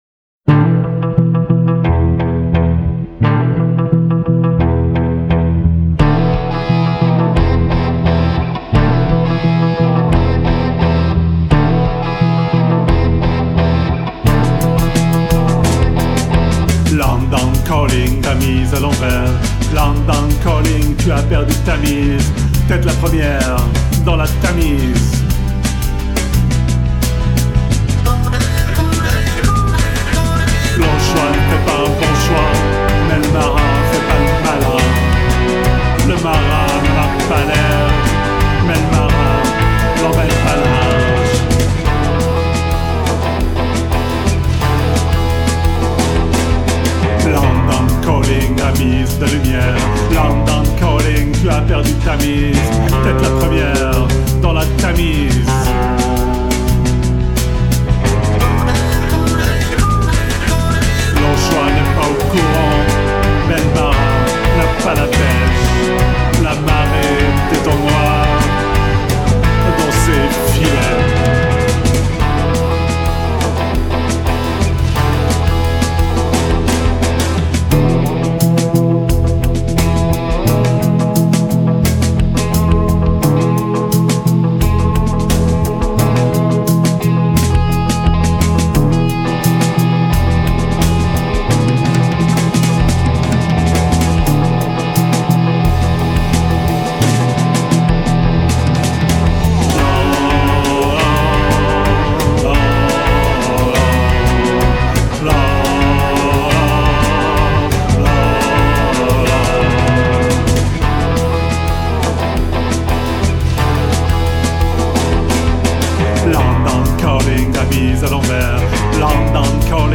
guitare, basse, clavier
Studio des Anges, Lausanne